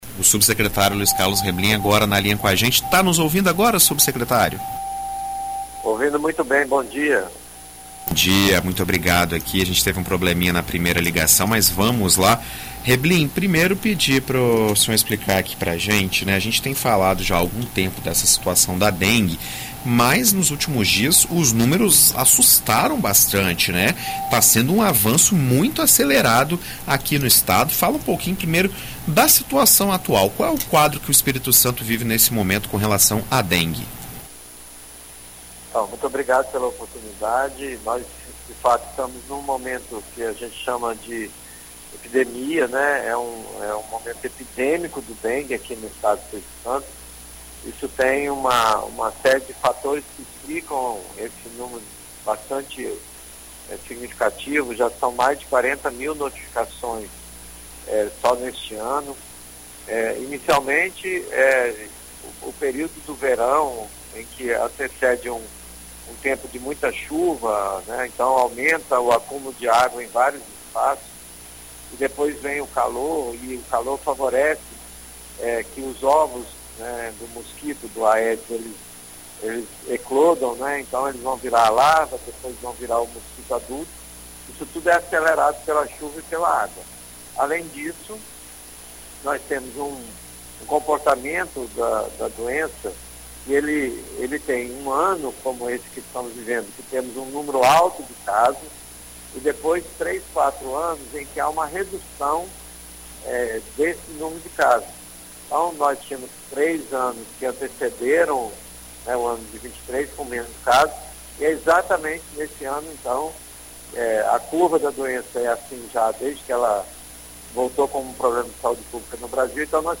Em entrevista à BandNews FM ES nesta sexta-feira (10), o subsecretário de Vigilância em Saúde do Espírito Santo, Luiz Carlos Reblin, fala sobre as medidas que estão sendo tomadas para reverter o cenário da doença no estado.